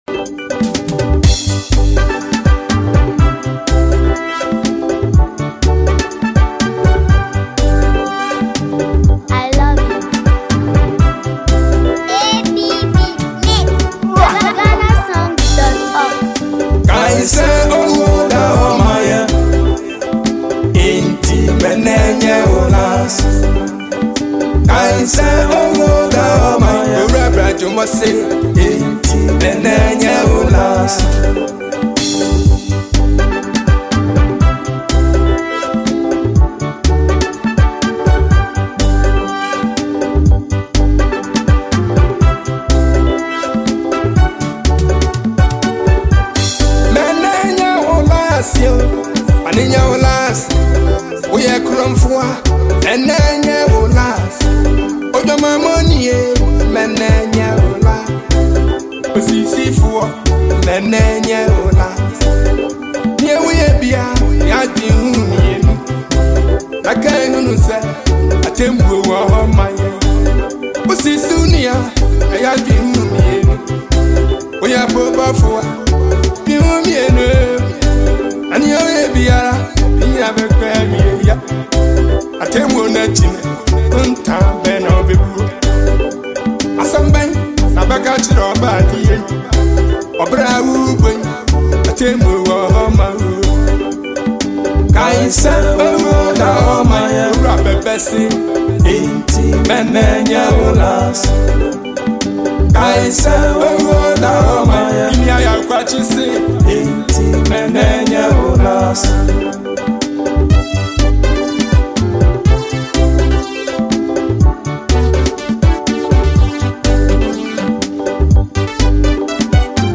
Ghana Highlife song MP3